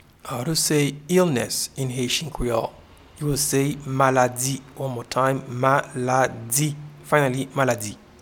Pronunciation and Transcript:
illness-in-Haitian-Creole-Maladi.mp3